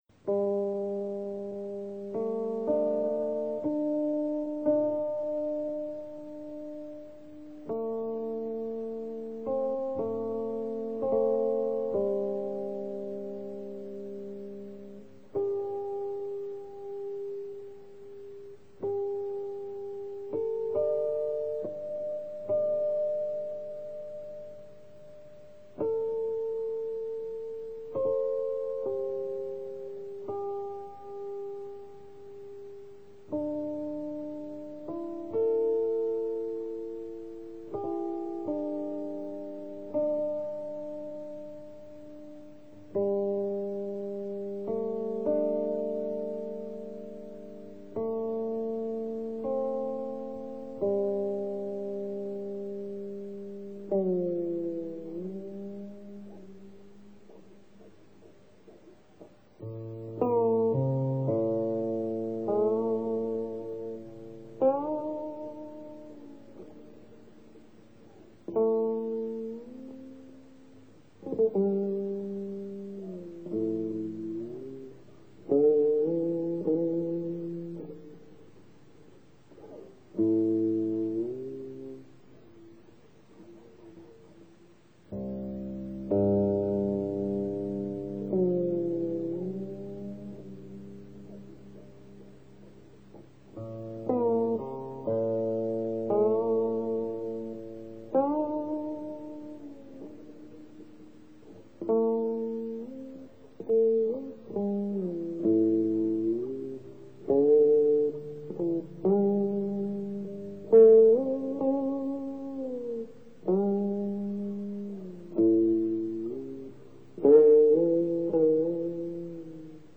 这套录音在50年代。刚好是人类录音技术开始有“专业化”机器的时代，经过系统的录音，所留下的古琴演奏曲。